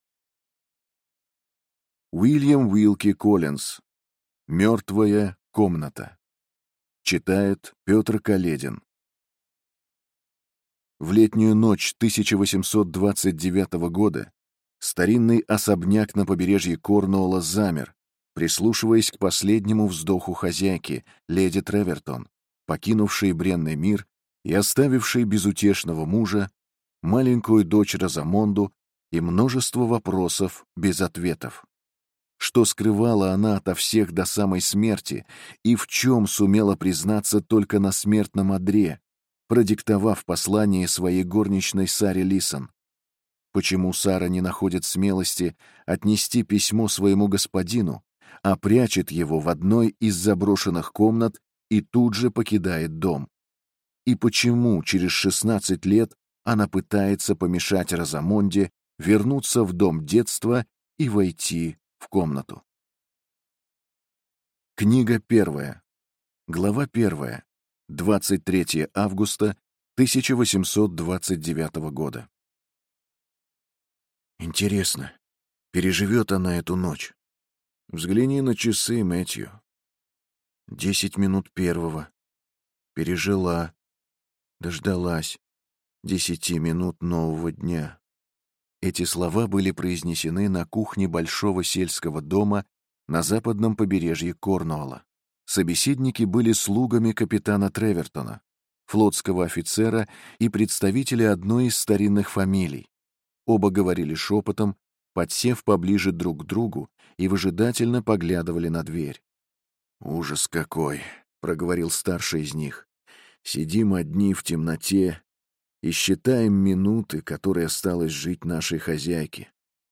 Аудиокнига Мертвая комната | Библиотека аудиокниг